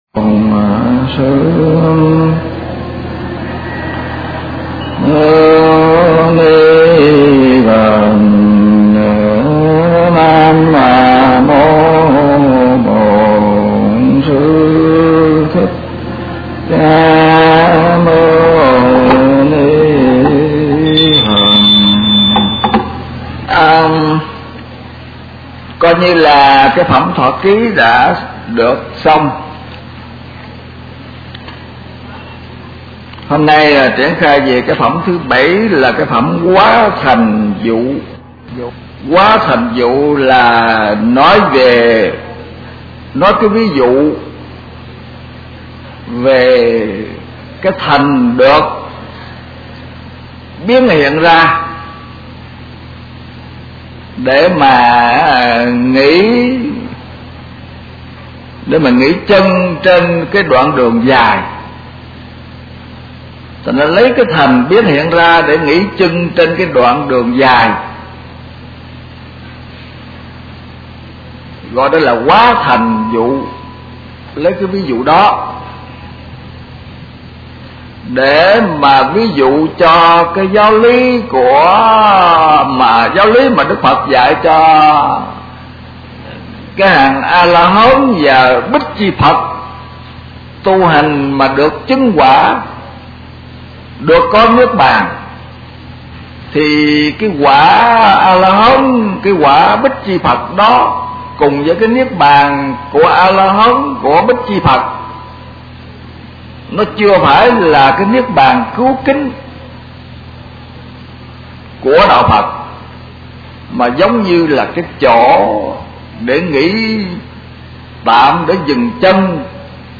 Kinh Giảng Kinh Pháp Hoa